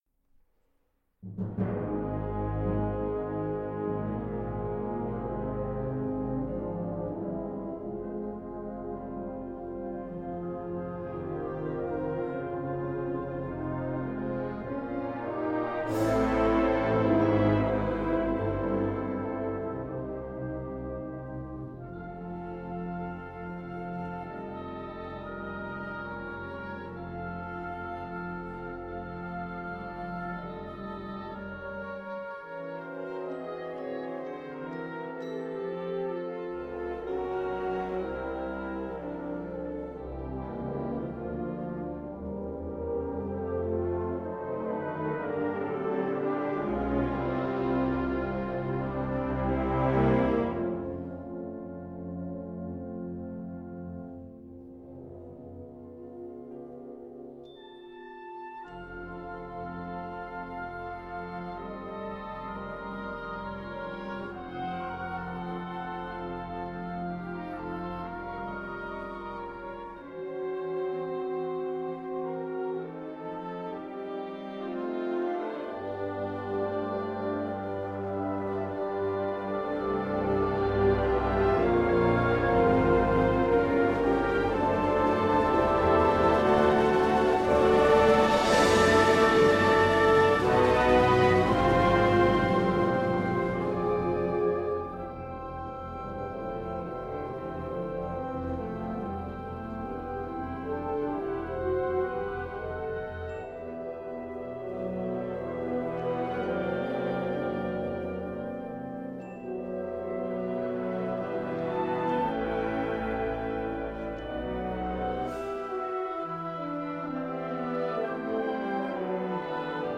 A Period Piece for Bands of Winds